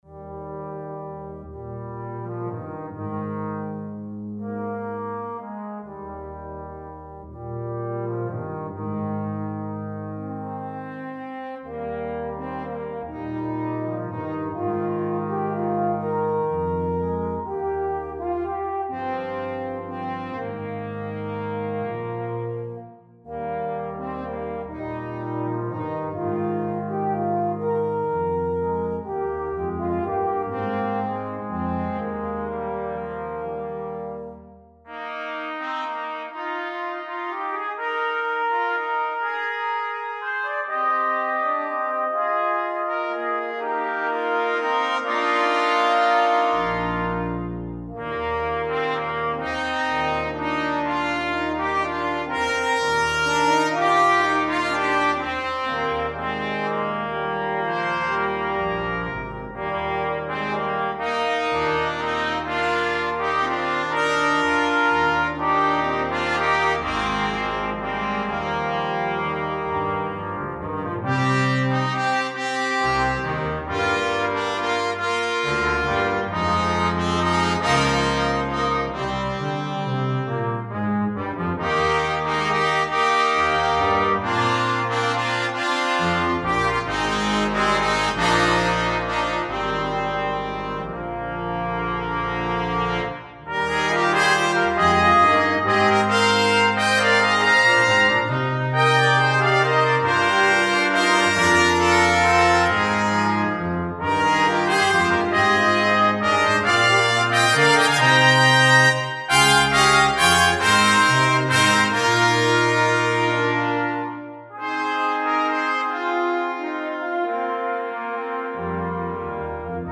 two B-flat trumpets, F horn, trombone, and tuba)Duration
It is bold, jaunty, and yet has a tragic undertone.